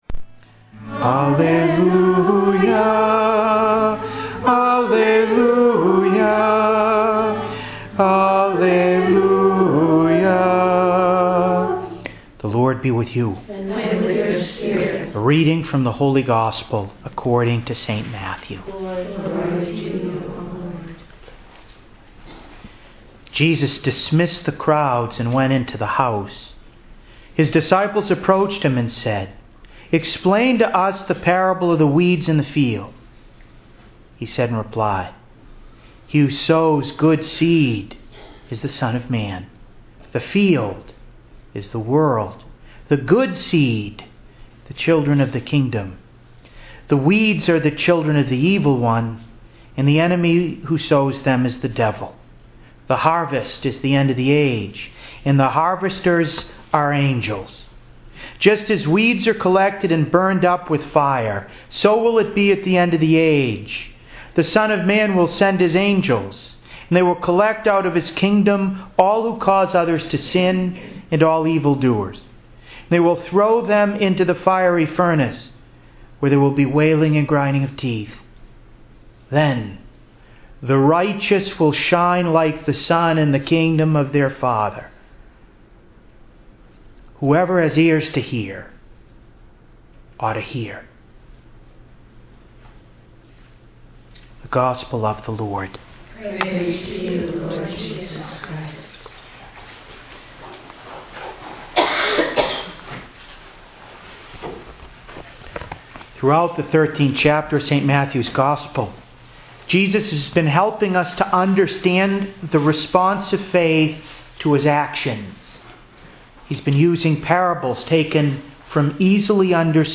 To listen to an audio of this homily, please click below: